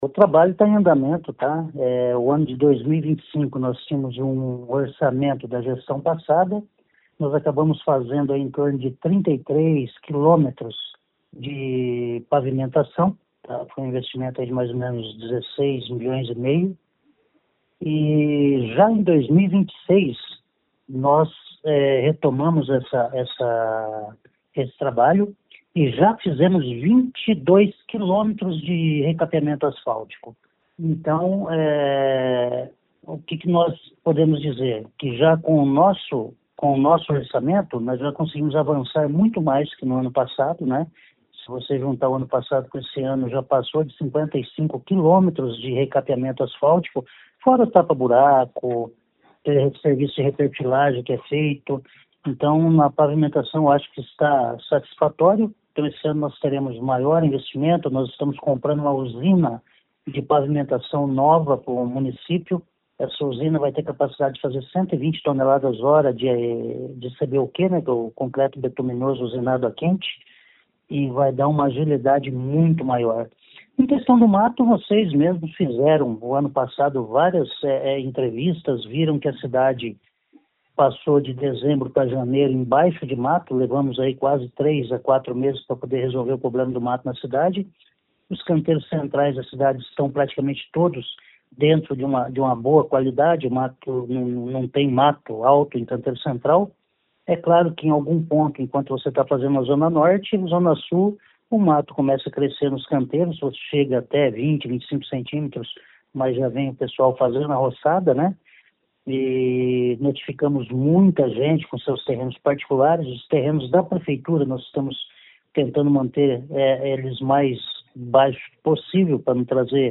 O secretário explica como está o trabalho de pavimentação e roçada.